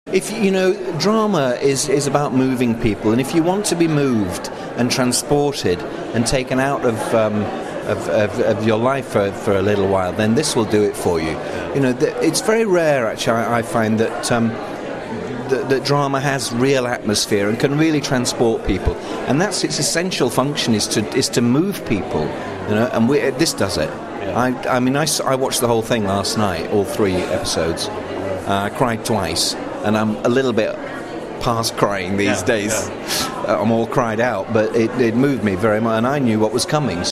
Here are a few short audio extracts from my chat with Peter: